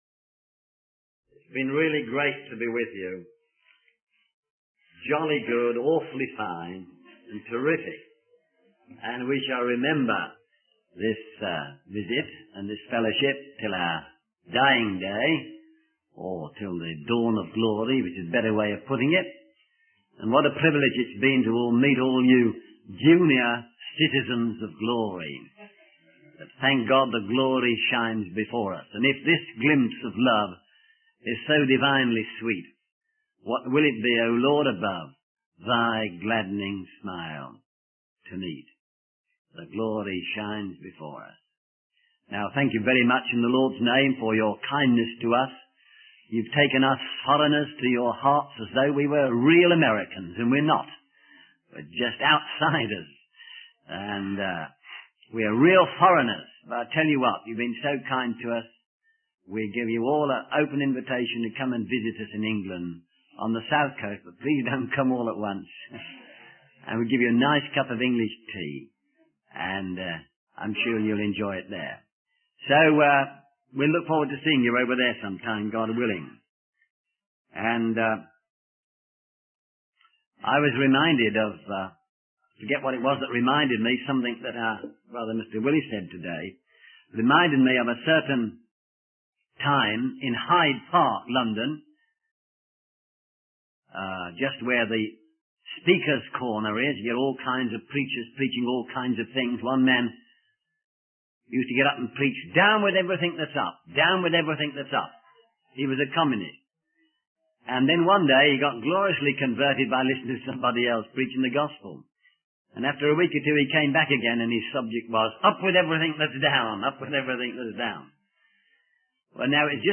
The story of Onesimus, a runaway slave who was reconciled with his master, is used as an analogy to highlight the transformative power of the gospel. The sermon also encourages believers to open their homes and share the gospel with their unsaved neighbors.